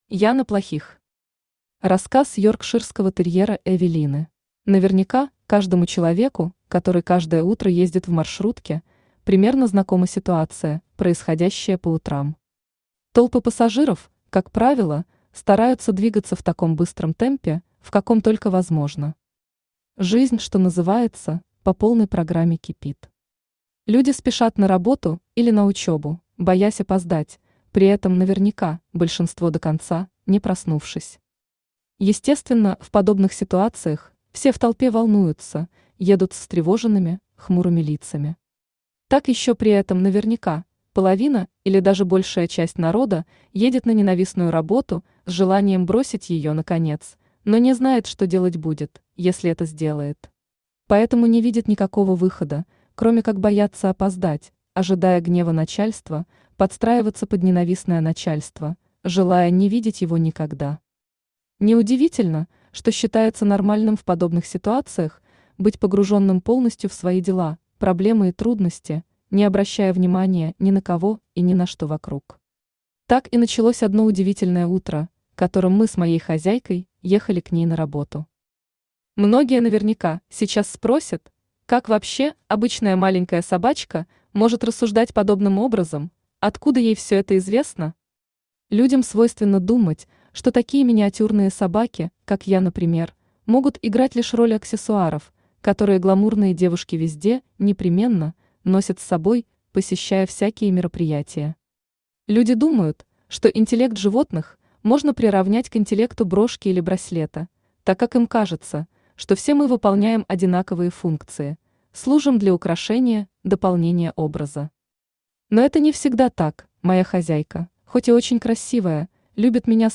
Aудиокнига Рассказ йоркширского терьера Эвелины Автор Яна Плохих Читает аудиокнигу Авточтец ЛитРес.